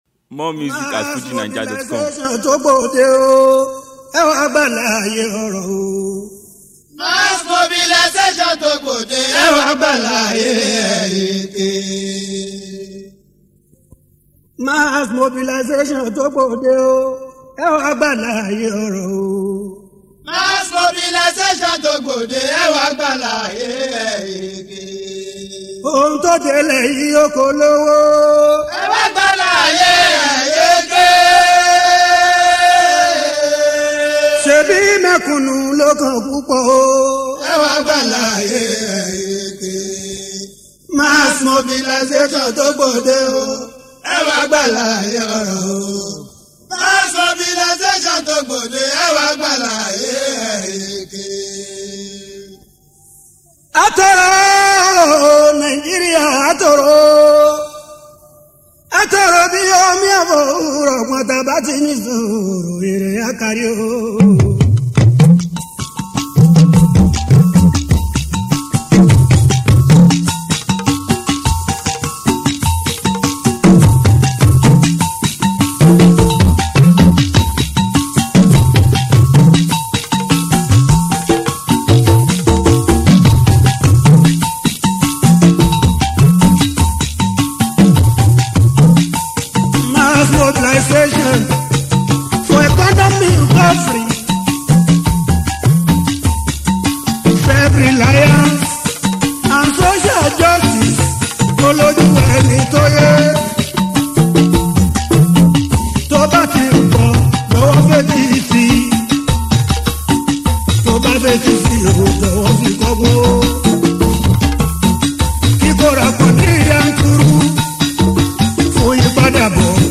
Legendary Yoruba fuji singer
old school track